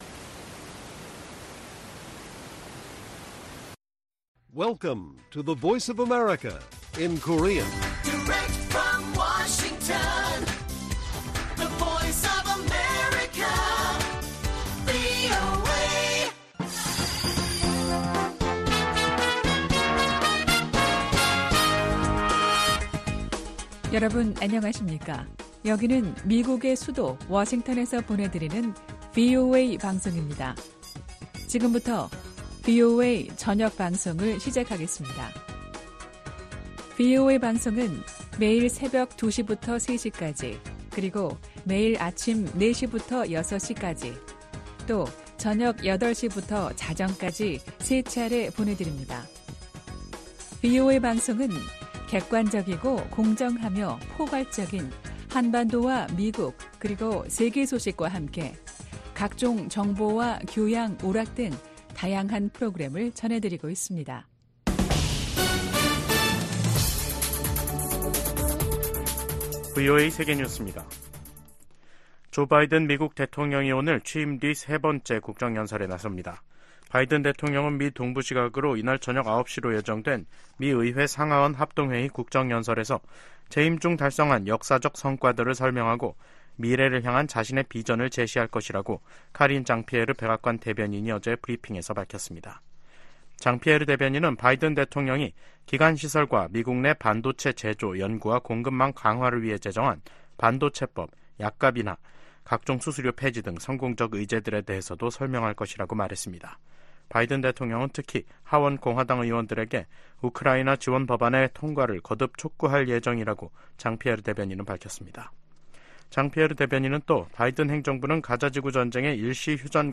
VOA 한국어 간판 뉴스 프로그램 '뉴스 투데이', 2024년 3월 7일 1부 방송입니다. 김정은 북한 국무위원장이 서부지구 작전훈련 기지를 방문해 전쟁준비 완성과 실전훈련 강화를 강조했다고 관영 매체들이 보도했습니다. 미국 정부는 현재 진행 중인 미한 연합훈련이 전쟁연습이라는 북한의 주장을 일축했습니다. 미국이 국제원자력기구(IAEA) 이사회에서 북한-러시아 탄도미사일 거래를 강력 규탄했습니다.